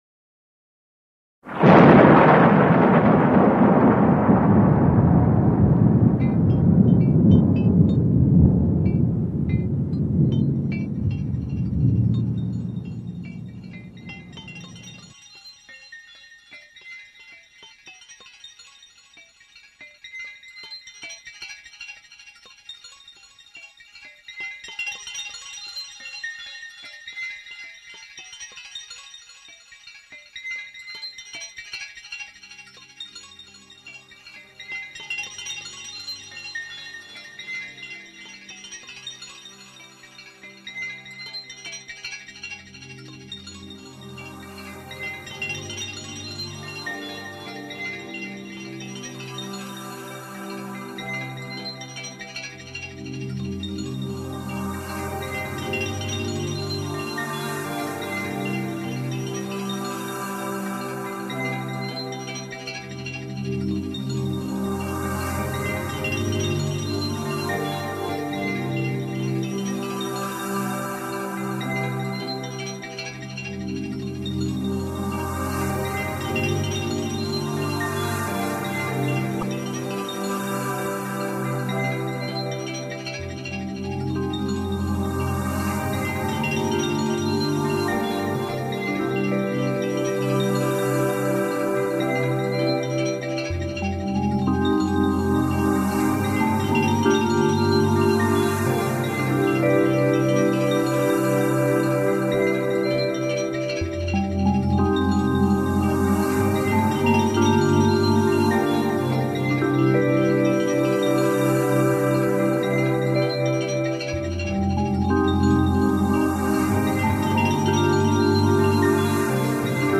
1h20 mix